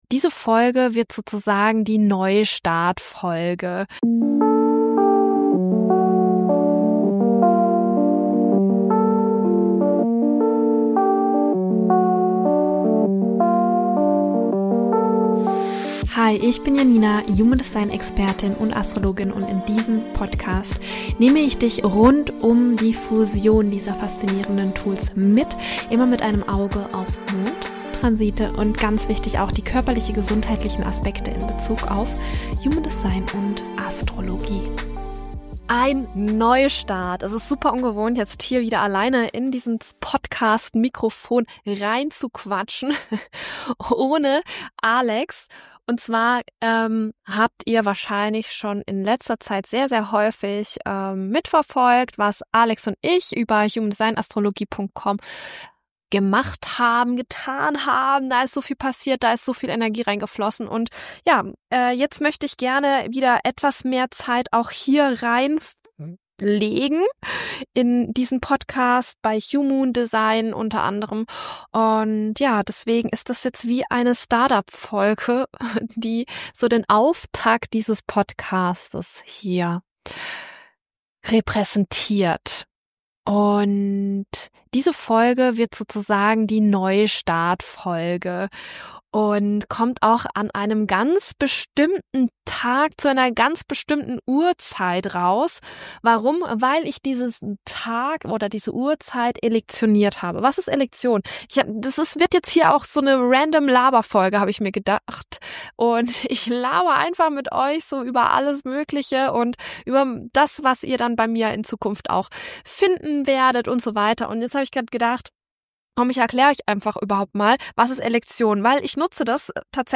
Es fühlt sich total ungewohnt an, wieder allein ins Mikro zu sprechen aber genau das macht diese Folge so besonders. Ich teile mit dir meine Learnings der letzten Monate, warum ich meine Preise anpasse, und wie das alles mit Selbstwert, offenem Herz/Egozentrum und astrologischen Techniken zusammenhängt.